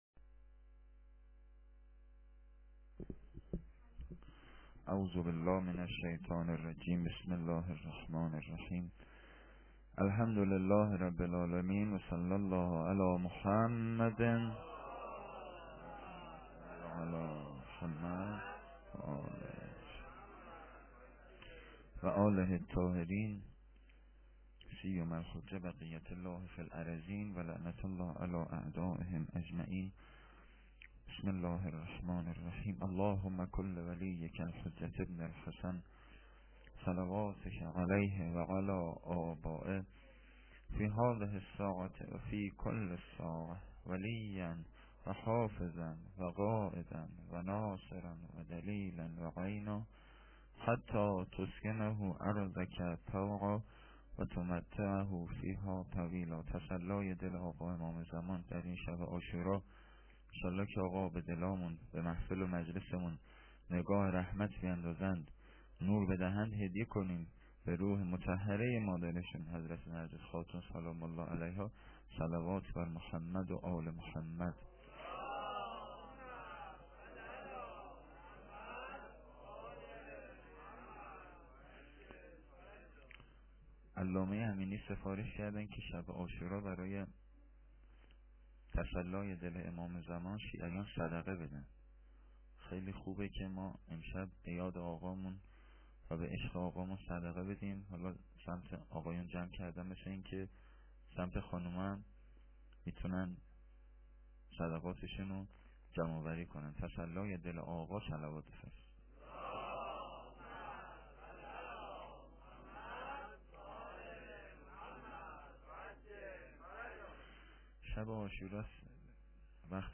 sokhanrani10.lite.mp3